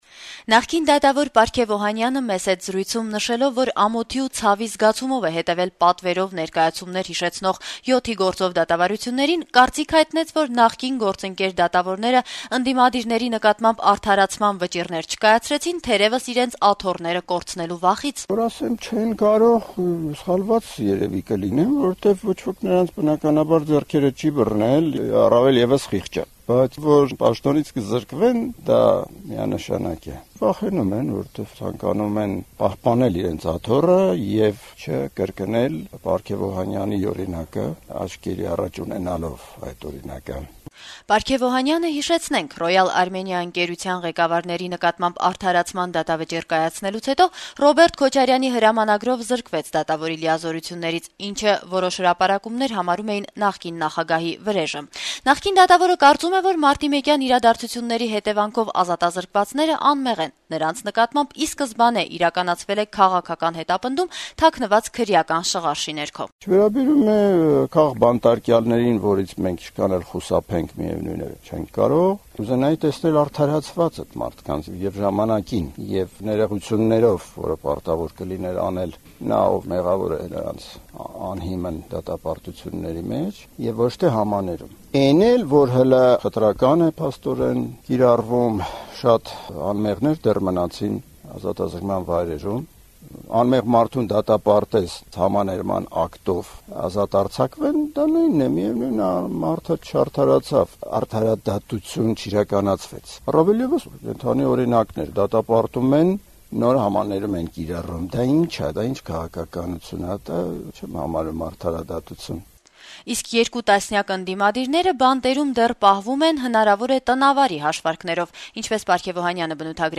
Նախկին դատավոր Պարգեւ Օհանյանը «Ազատություն» ռադիոկայանի հետ զրույցում, նշելով, որ ամոթի ու ցավի զգացումով է հետեւել պատվիրված ներկայացումներ հիշեցնող «7-ի գործով» դատավարություններին, կարծիք հայտնեց, որ իր նախկին գործընկեր դատավորները արդարացման վճիռներ չկայացրեցին` իրենց աթոռները կորցնելու վախից: